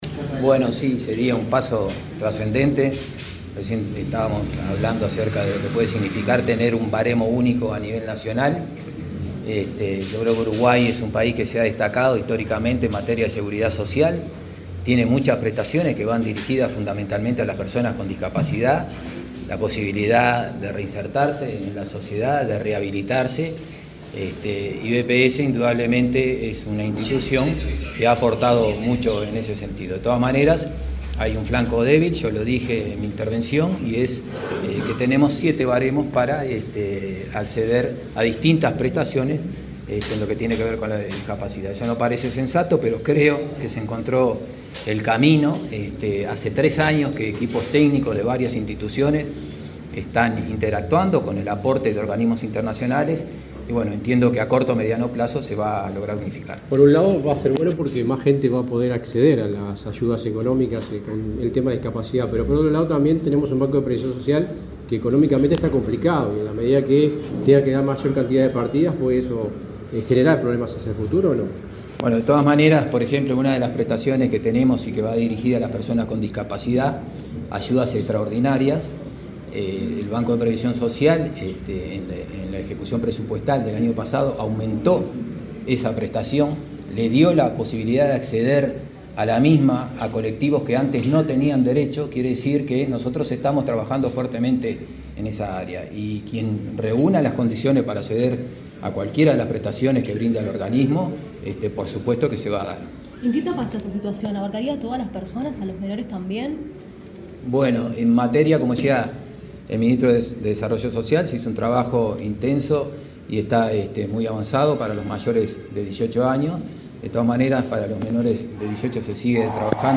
Declaraciones del presidente del BPS
El presidente del Banco de Previsión Social (BPS), Daniel Graffigna, dialogó con la prensa luego de participar en la presentación de avances del